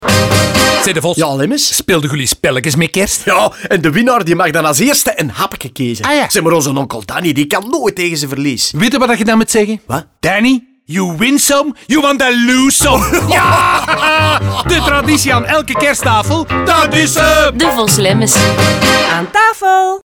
In de nieuwe radiospots komen ze smakelijk aan bod.
DevosLemmens_NL20s_Gezelschapspel_Radio.mp3